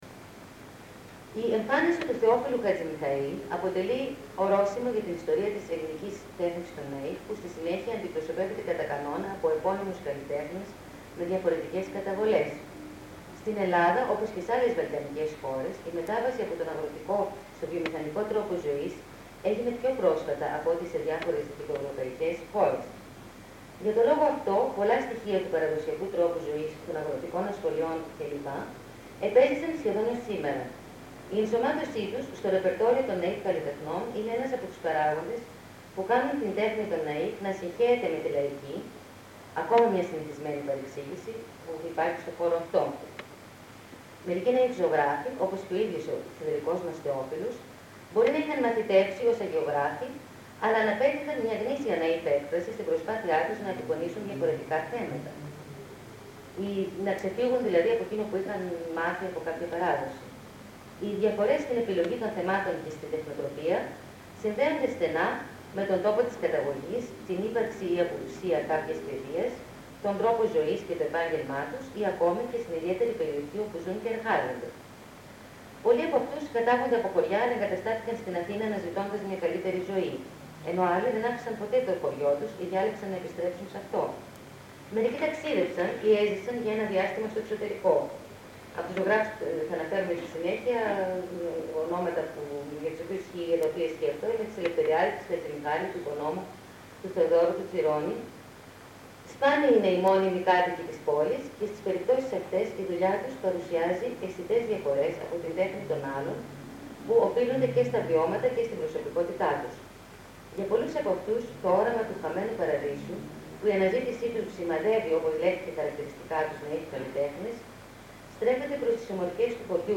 Κύκλος ομιλιών στο ΛΕΜΜ-Θ.
Β' κύκλος ομιλιών. (EL)